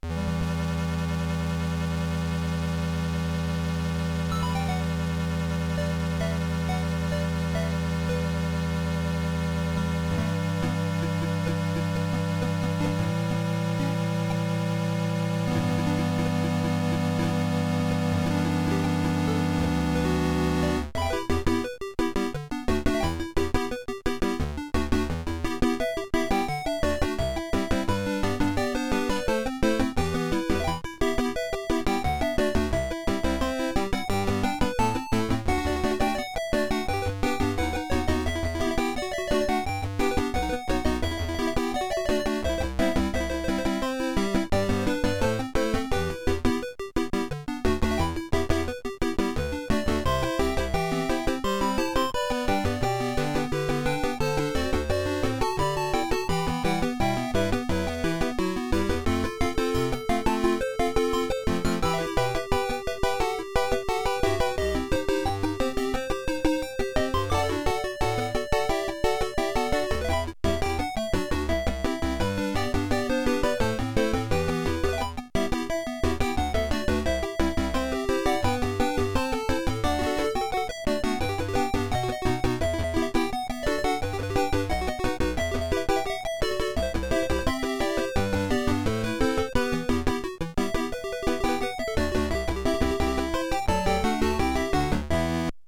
Creative SoundBlaster 1.5 ct1320(CMS)